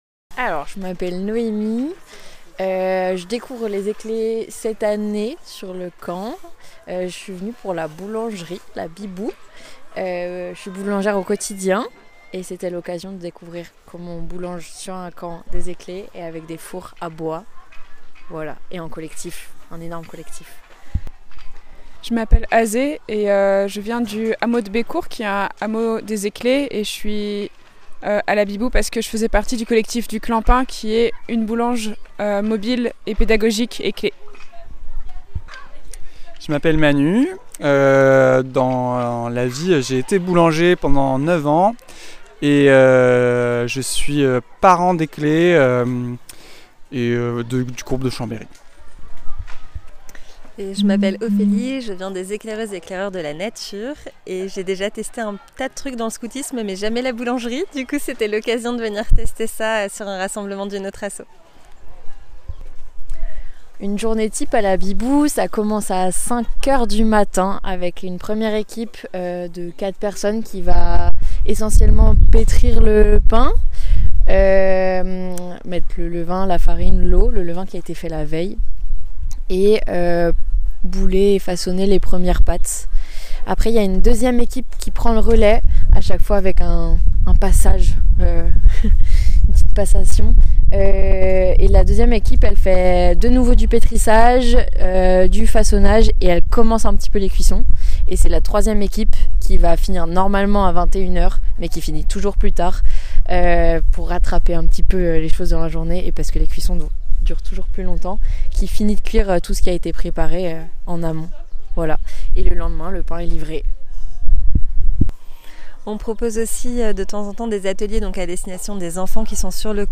Un entretien à quatre voix, qui nous a fait découvrir l’envers du décor d’un lieu chaleureux, nourrissant… et plein de levain.